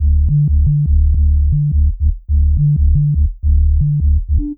000-sine-bass.wav